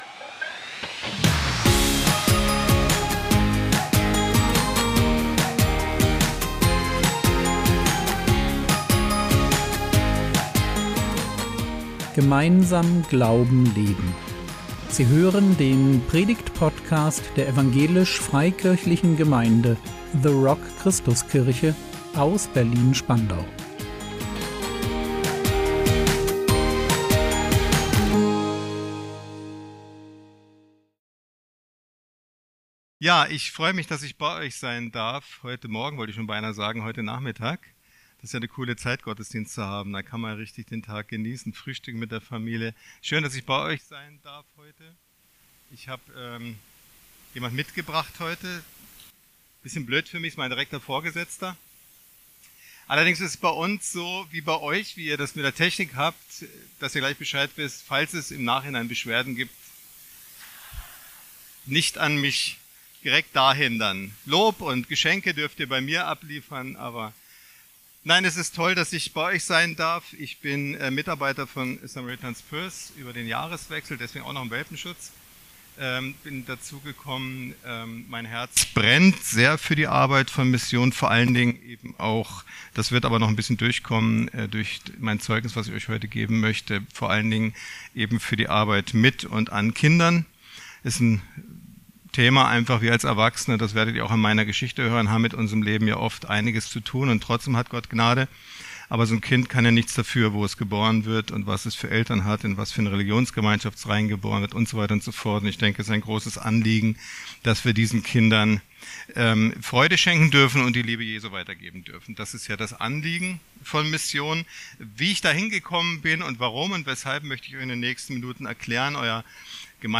Vom Langfinger zum Christ | 18.05.2025 ~ Predigt Podcast der EFG The Rock Christuskirche Berlin Podcast
Zeugnis